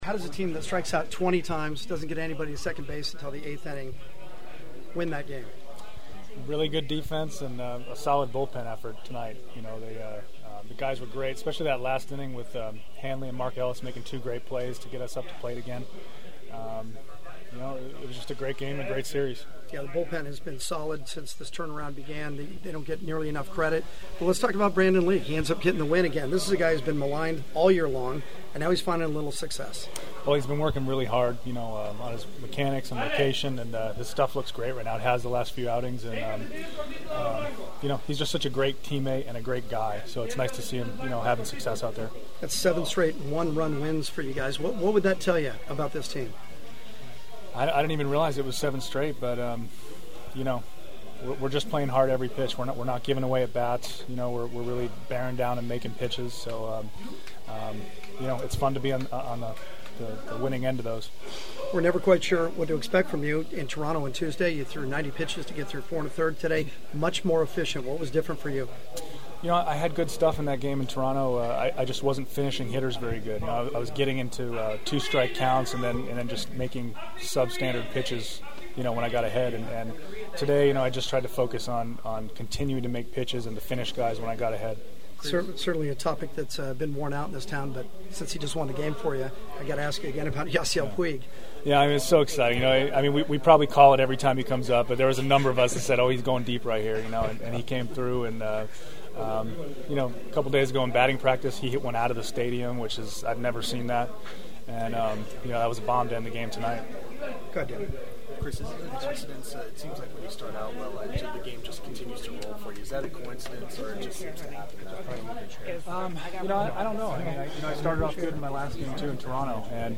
The following are my postgame clubhouse interview including some preview thoughts on the Yankees invading Dodger Stadium for 2 nights starting on Tuesday.
Dodger starter Chris Capuano who threw an impressive 3-hitter thru 6 2/3 innings: